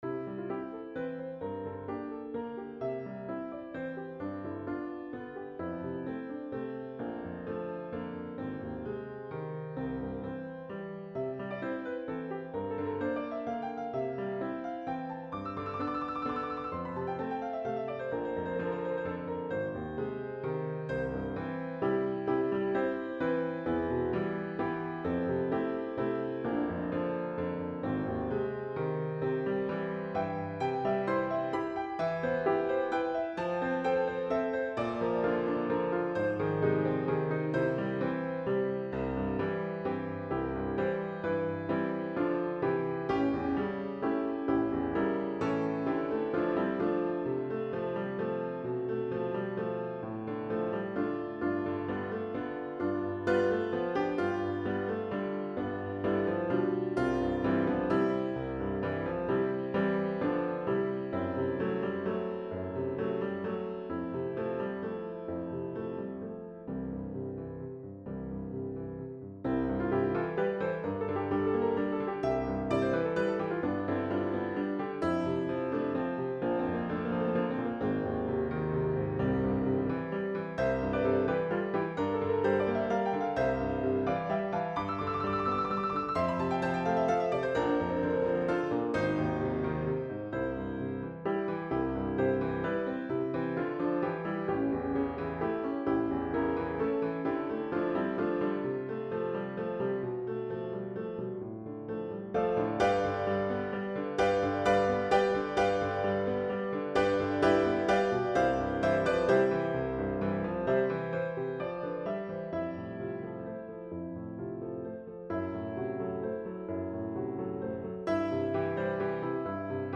This song is a vibrant waltz, composed in the key of C major. It is essentially in three part, AB form. The A melody actually has two distinct versions, A1 and A2 both introduced in the beginning of the music. The overall structure is: A1A2BA1BA2BA1Coda.